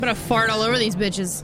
Play, download and share teenFARTBITCHES original sound button!!!!
teenfart_vAUTFtP.mp3